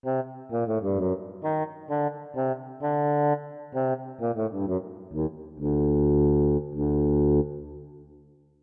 Música ambiental del cuento: La princesa Ranita
ambiente
melodía
sintonía